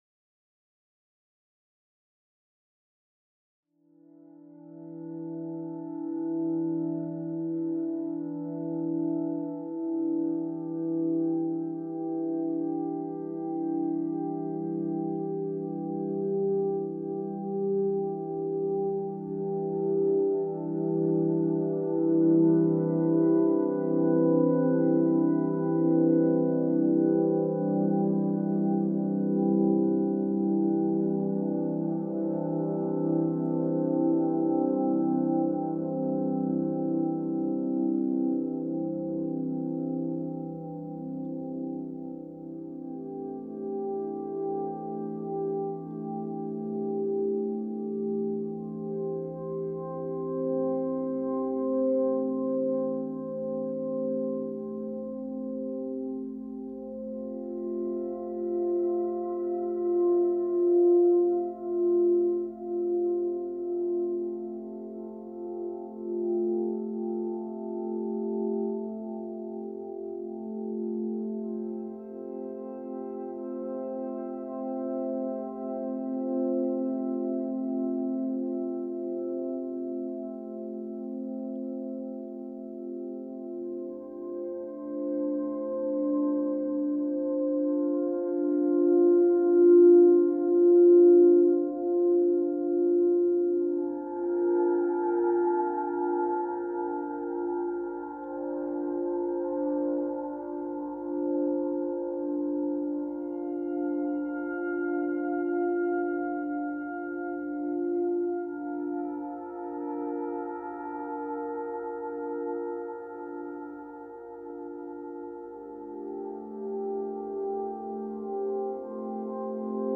In Sympathy Music for CymaPhone A portfolio of sympathetic resonance compositions enlightened by the mystical forms of rāga and sound healing.